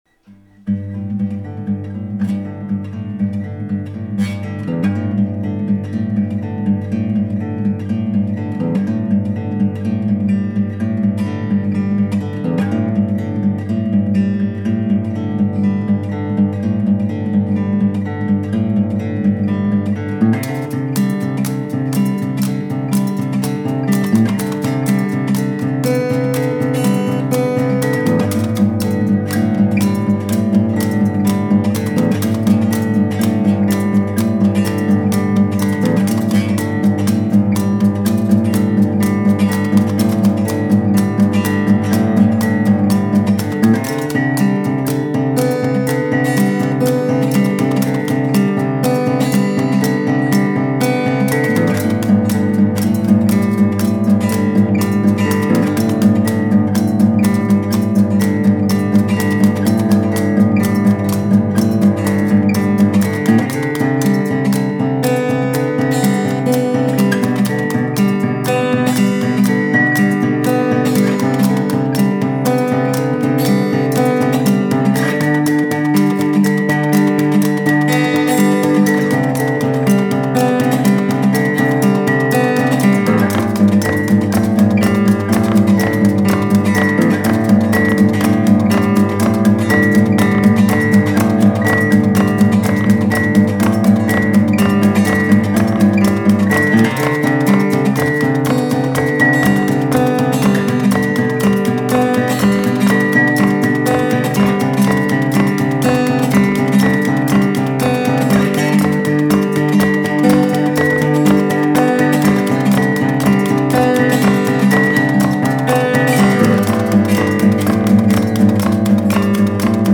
guitar
banjo
bulbul tarang